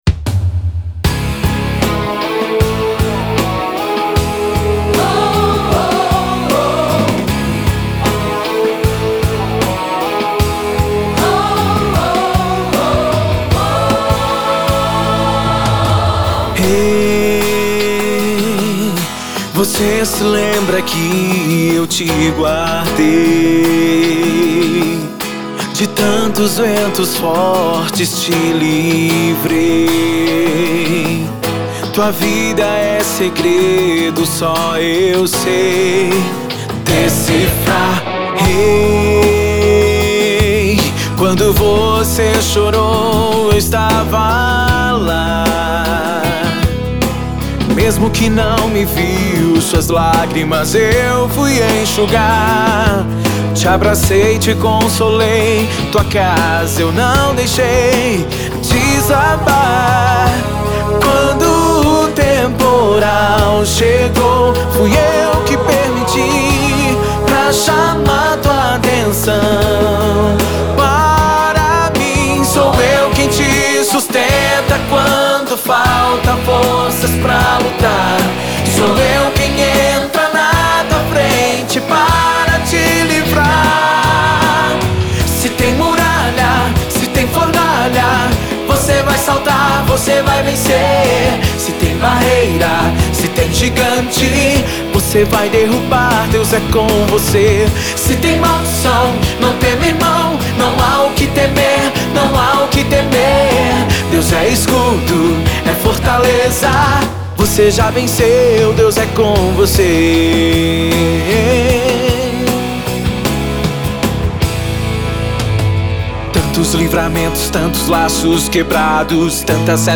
O álbum traz músicas em um estilo de adoração pop.